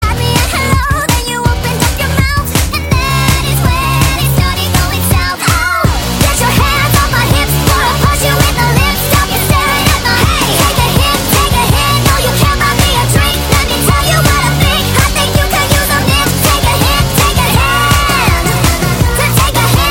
Kategorien: Elektronische